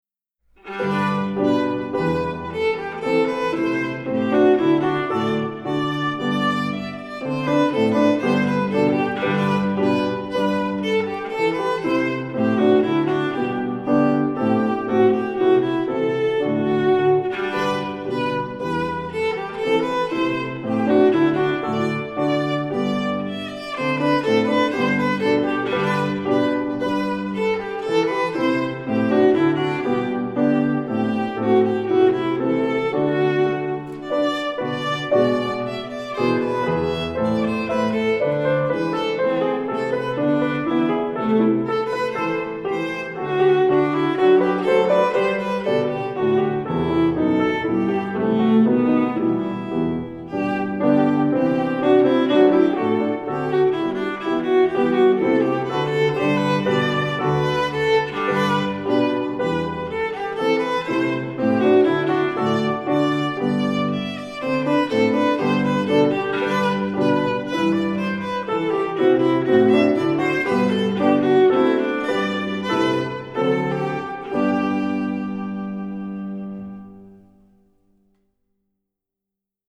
Voicing: 4 Viola